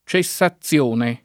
cessazione [ © e SS a ZZL1 ne ] s. f.